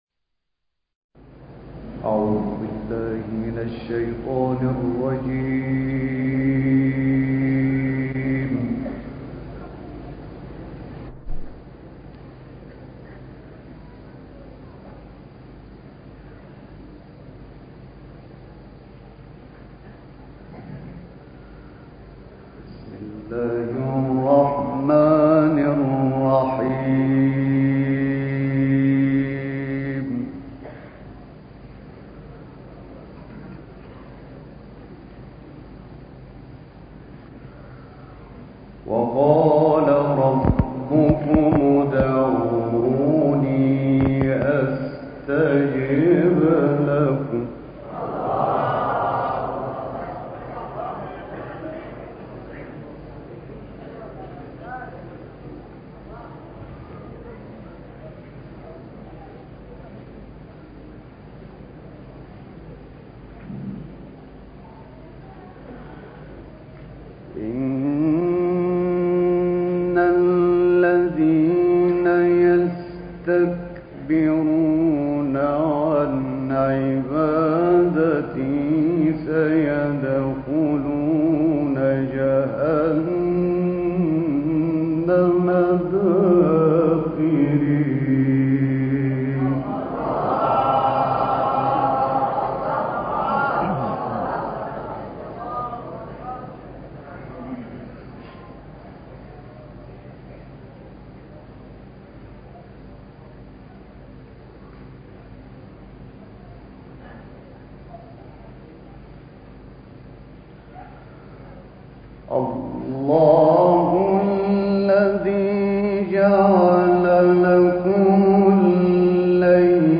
گروه شبکه اجتماعی: تلاوت سوره‌هایی از قرآن کریم توسط محمود صدیق منشاوی و شعبان عبدالعزیز صیاد که در سال 1370 در شهر رشت اجرا شده است، ارائه می‌شود.
محمود صدیق منشاوی آیاتی از سوره‌های غافر، بلد، قارعه و شعبان عبدالعزیز صیاد آیاتی از سوره‌های نجم و قمر را در این برنامه تلاوت کرده‌اند.